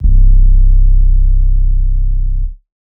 808 (FreakInYou).wav